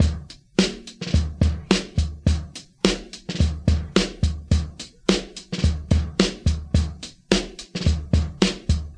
107 Bpm 00's Drum Loop D# Key.wav
Free breakbeat - kick tuned to the D# note.
107-bpm-00s-drum-loop-d-sharp-key-DCC.ogg